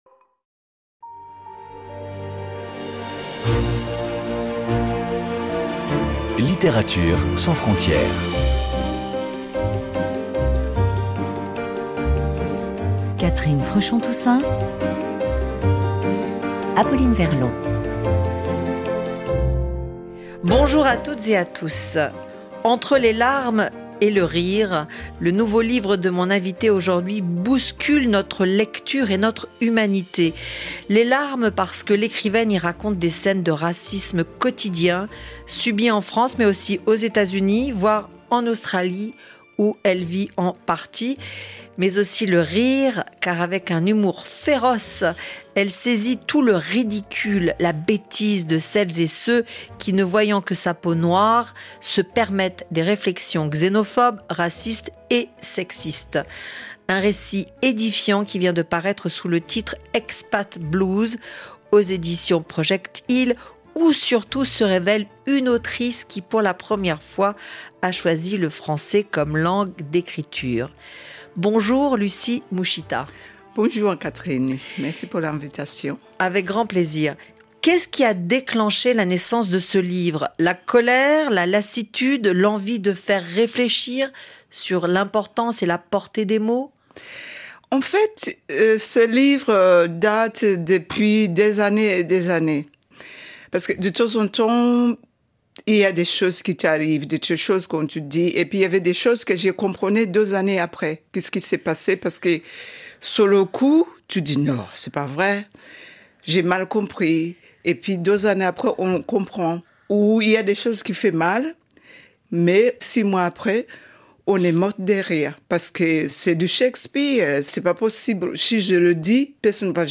L’entretien